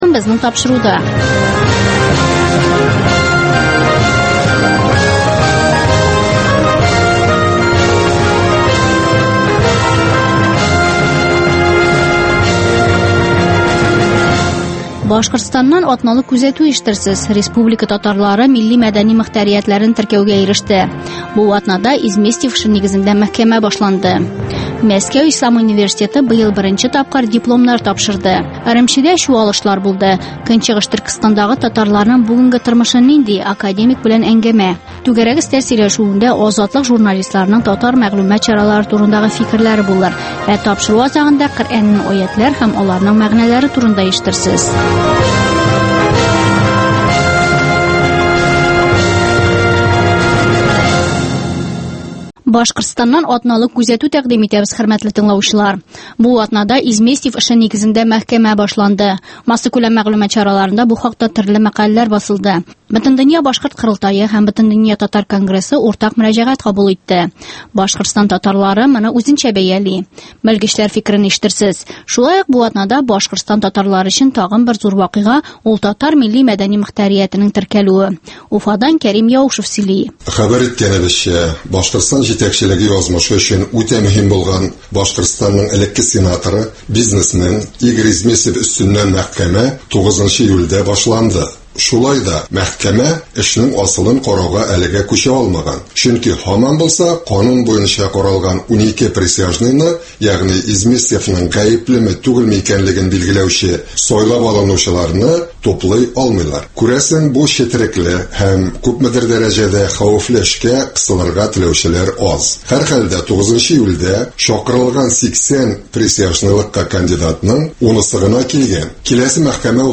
Азатлык узган атнага күз сала - башкортстаннан атналык күзәтү - татар дөньясы - түгәрәк өстәл артында сөйләшү